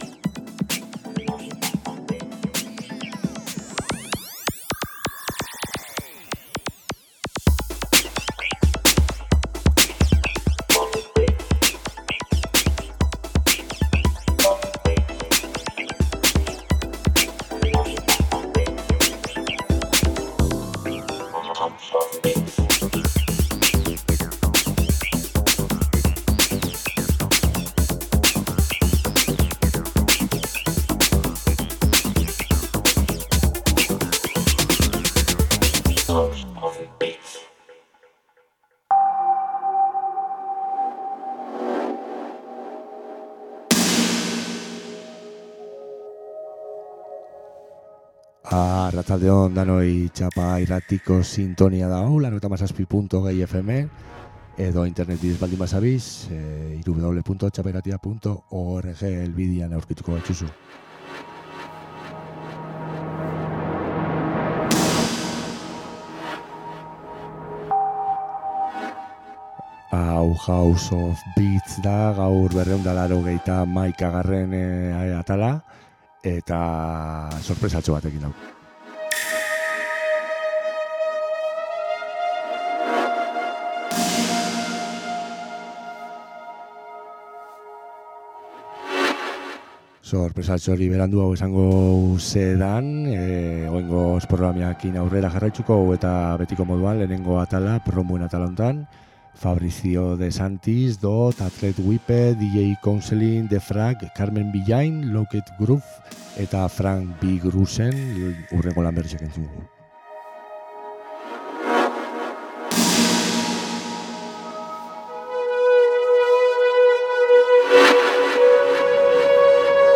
Txapa irratian elektronika kultura sustatzen duen irratsaioa. Elkarrizketak, sesioak, jai alternatiboen berriak eta musikaz gozatzeko asteroko saioa.